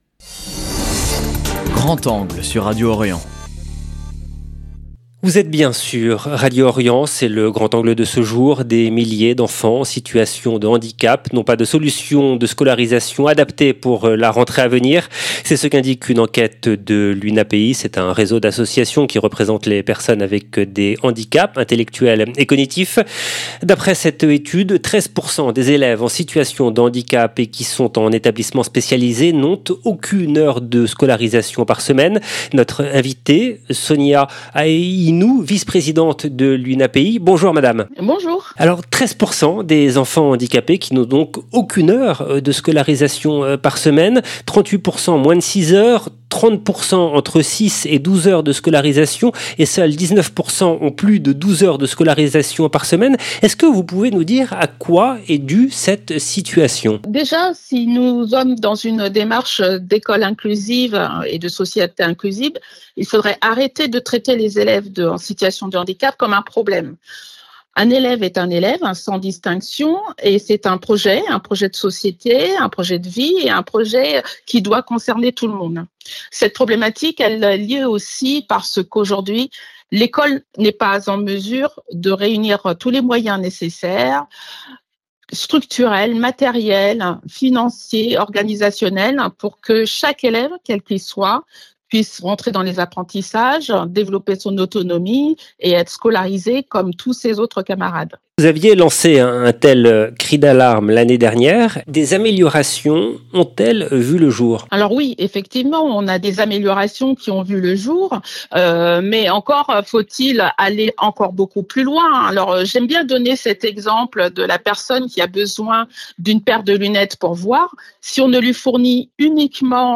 D’après cette étude, 13 % des élèves en situation de handicap et qui sont en établissements spécialisés n'ont aucune heure de scolarisation par semaine. Entretien